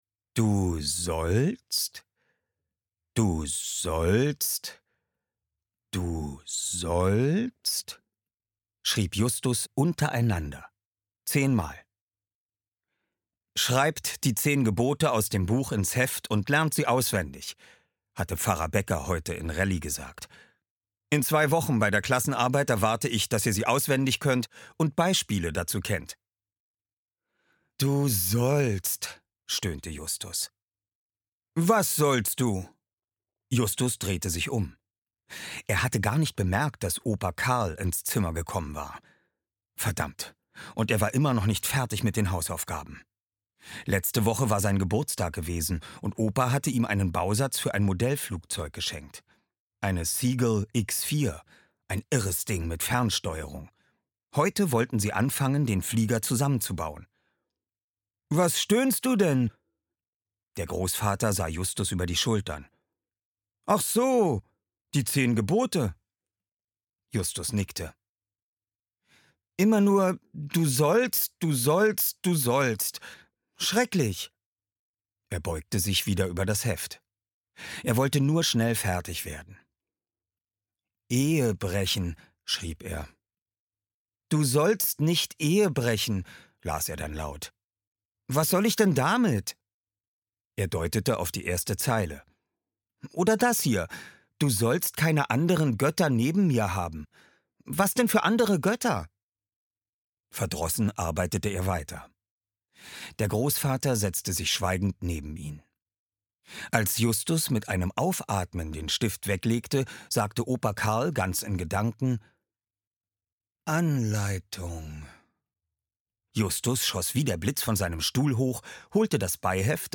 Oliver Rohrbeck (Sprecher)